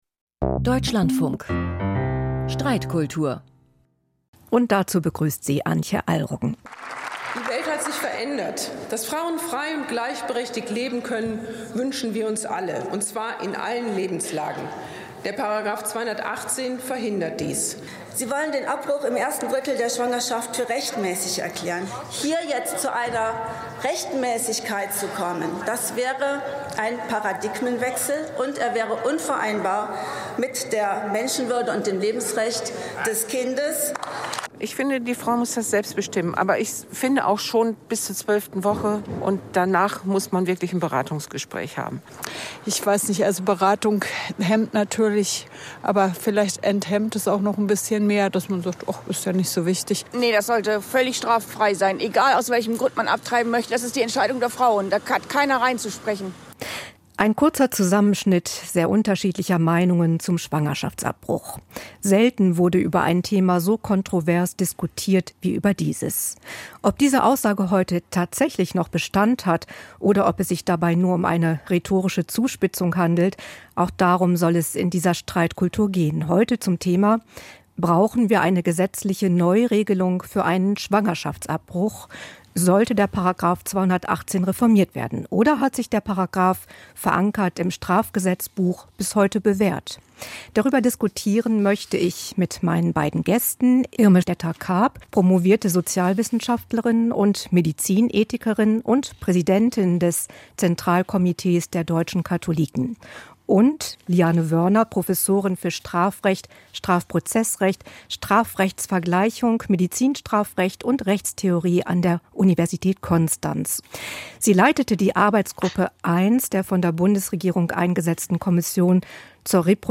Streitgespräch - Streitkultur
Eine zugespitzte Frage, zwei Gäste, zwei konträre Positionen - dazu eine Moderatorin oder ein Moderator und ein weites Themenspektrum, jeden Samstag um 17.05 Uhr. Das ist das Konzept der neuen Sendung "Streitkultur".